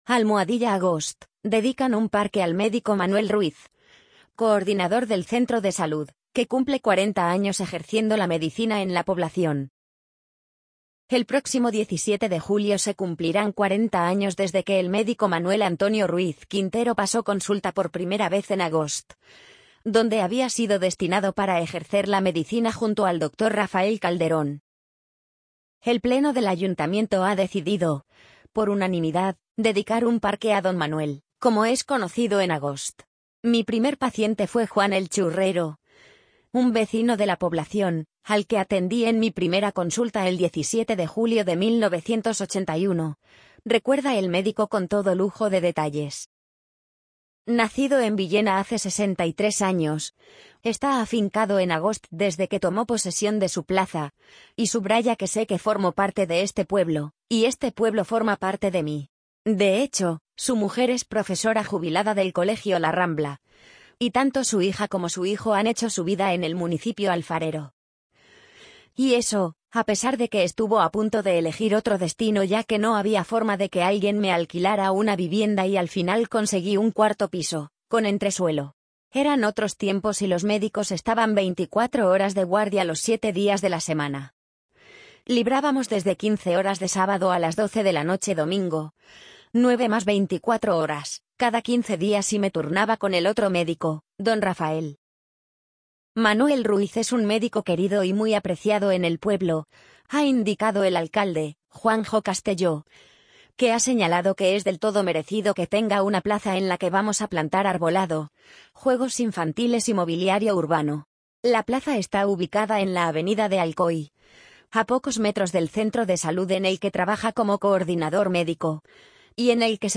amazon_polly_50488.mp3